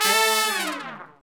Index of /90_sSampleCDs/Roland L-CD702/VOL-2/BRS_R&R Horns/BRS_R&R Falls